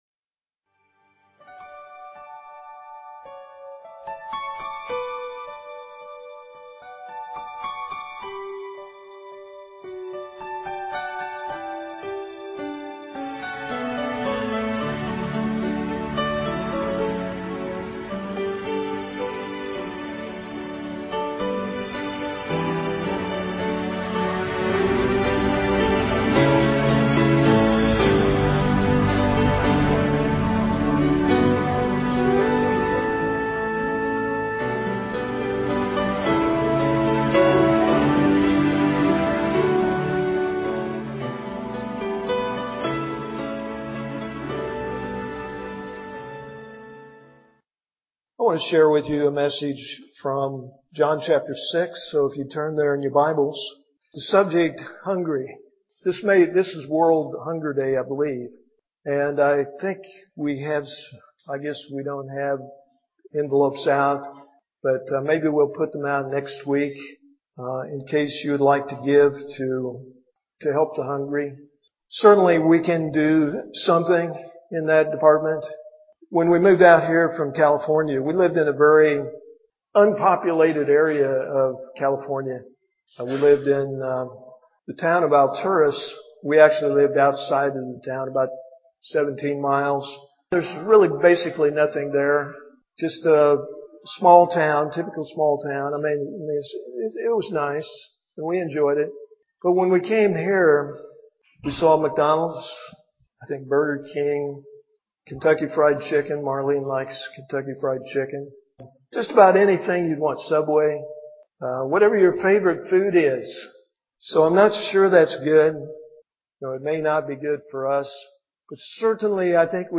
at Ewa Beach Baptist Church. Musical Intro/Outro: "How Beautiful."